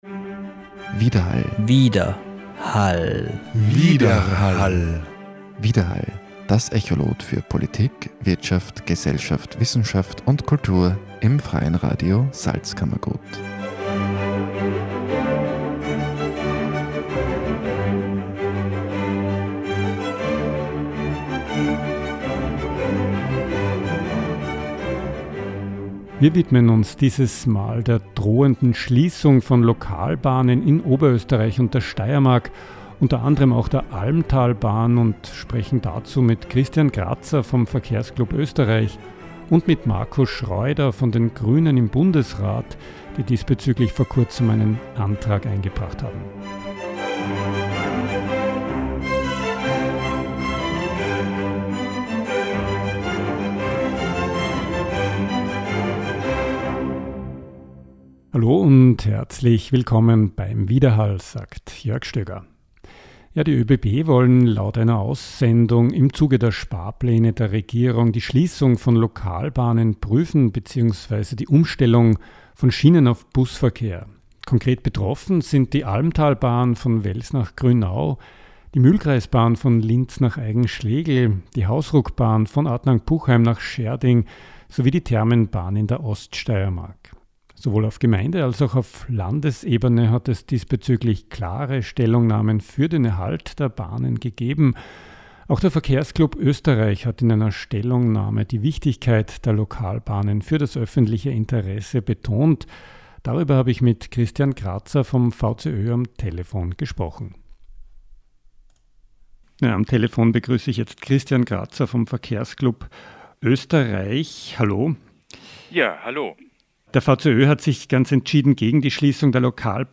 Dazu ein Interview
Darüber ein Gespräch Marco Schreuder von den Grünen (Bild rechts).